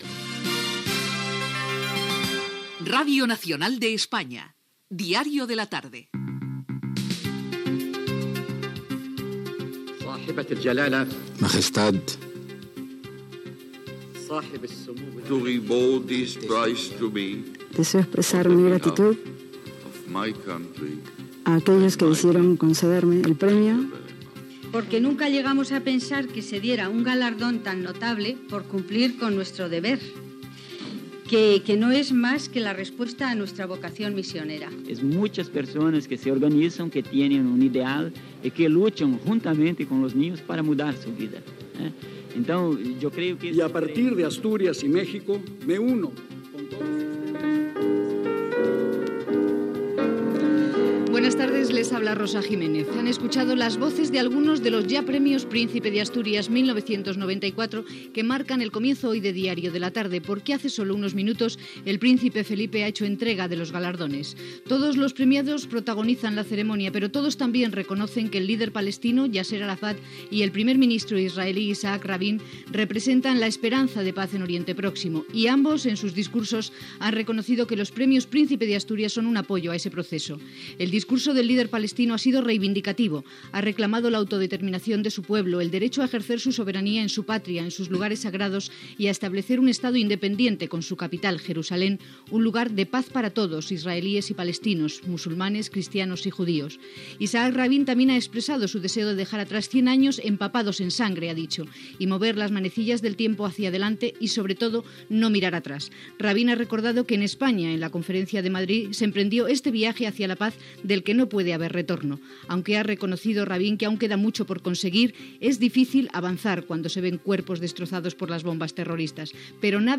Indicatiu del programa, lliurament dels Premios Príncipe de Asturias, amb l'assistència dels presidents Iàssir Arafat de Palestina i Isaac Rabín d'Israel
Informatiu